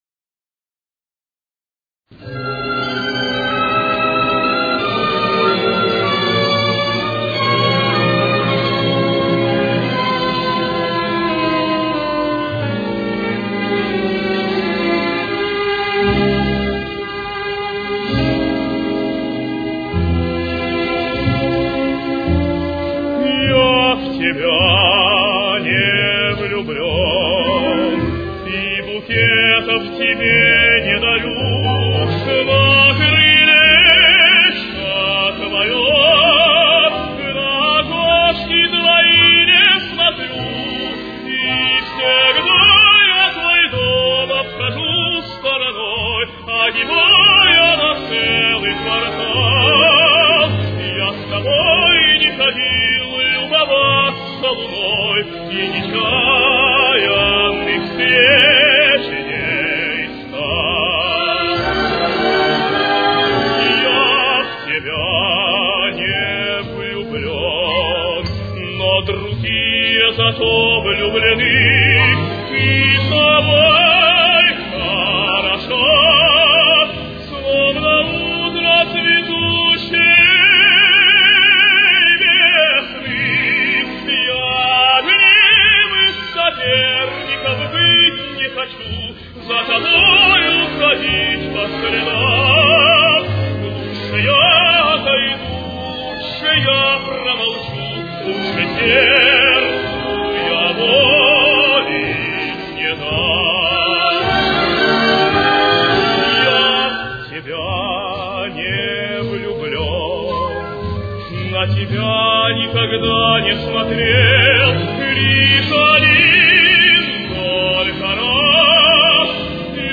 Темп: 111.